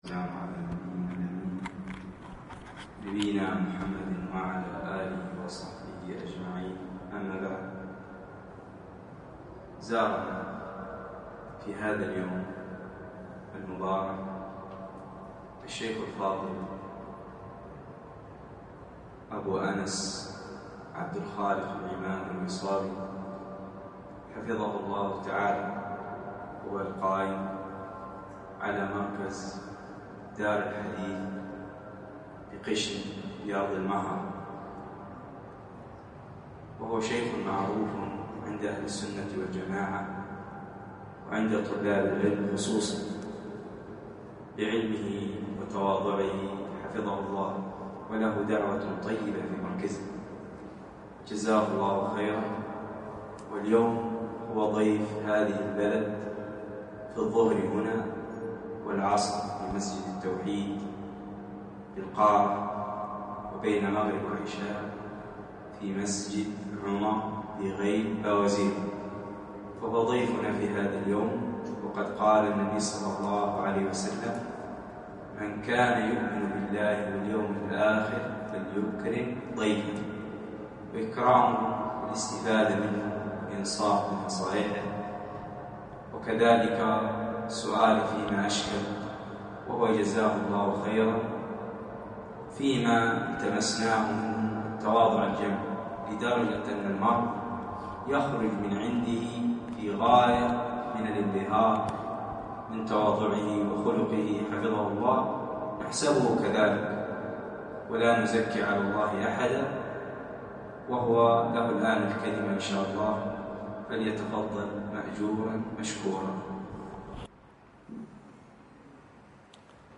كلمة الظهر بصداع